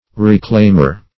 Reclaimer \Re*claim"er\ (r[-e]*kl[=a]m"[~e]r), n.